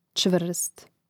čvȓst čvrst prid.